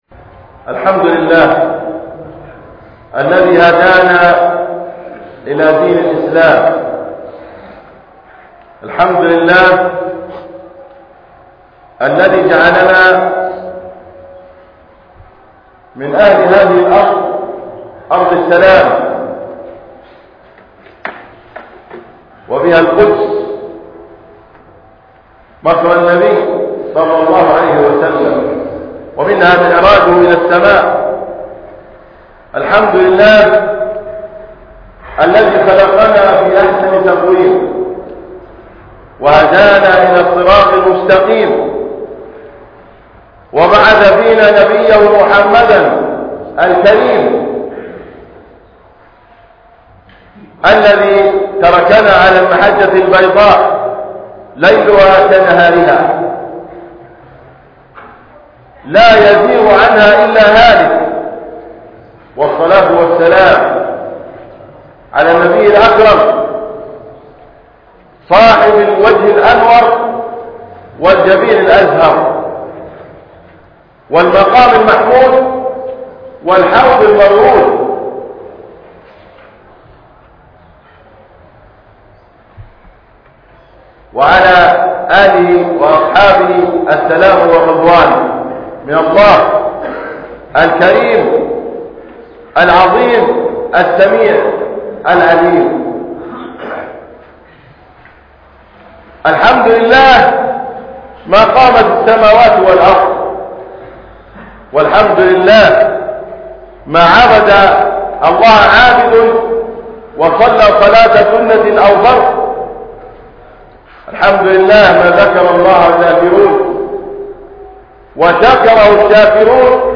بمســجد الحـــمد – خان يونس